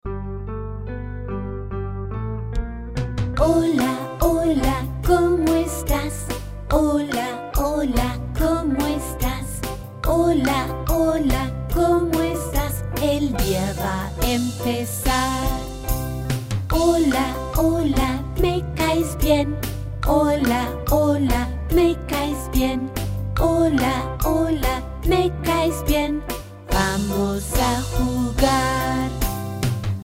Greetings Song for Learning Spanish